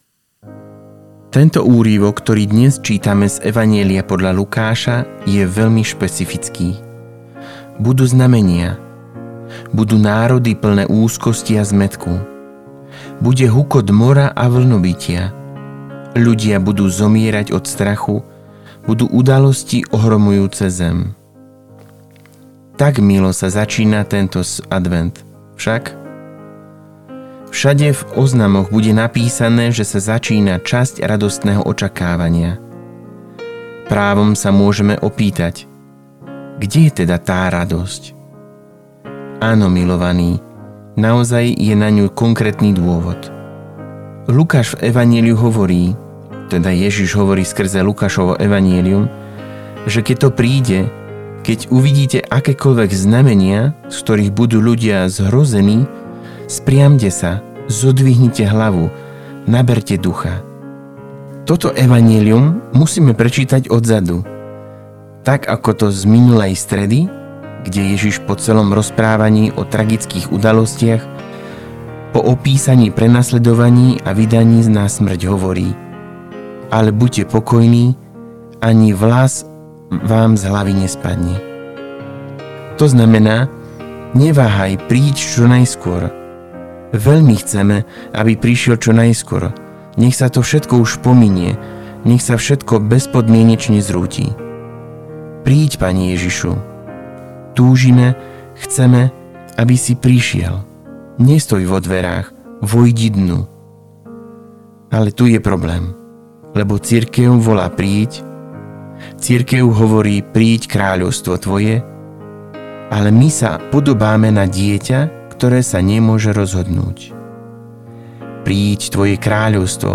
Audio zamyslenie na 1. adventnú nedeľu: Veľmi dôležitá a pekná je tá prvá veta, v ktorej sa podrobne opisuje, kedy pôsobil